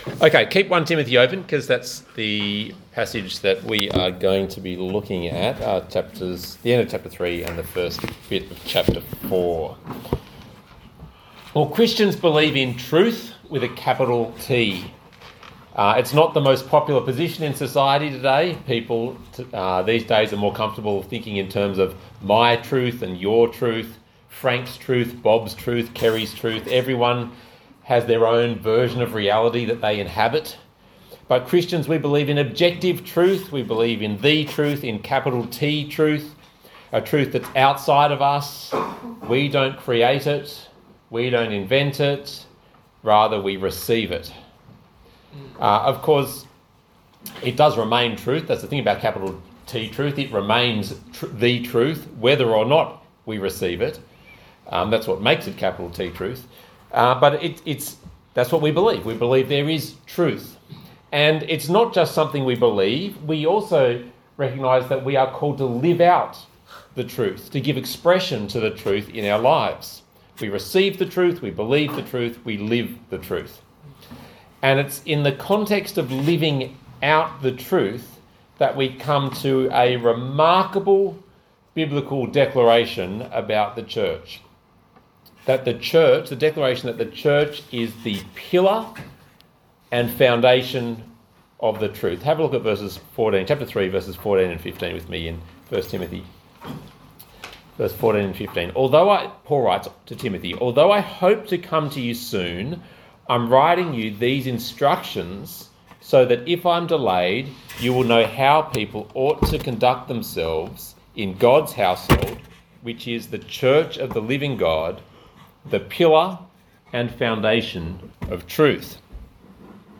Talk Type: Bible Talk Topics: godliness , Jesus , religion